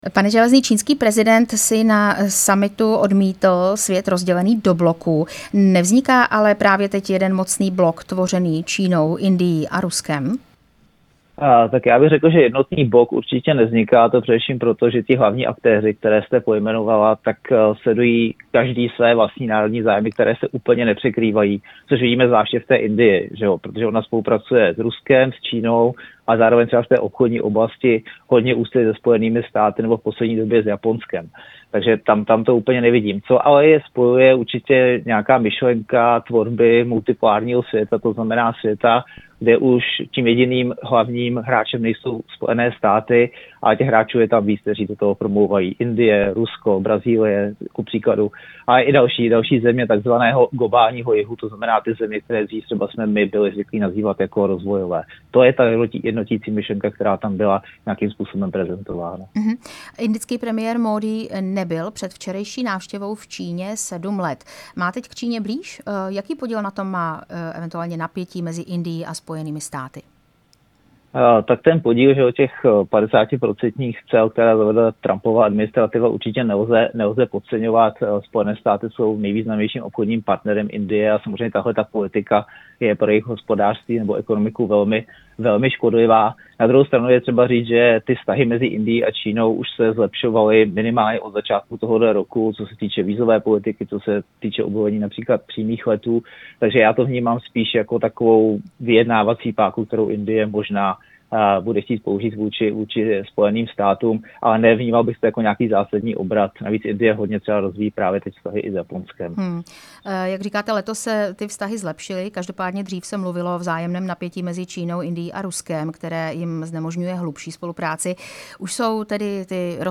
Zahraniční politika